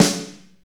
Index of /90_sSampleCDs/Northstar - Drumscapes Roland/DRM_Slow Shuffle/SNR_S_S Snares x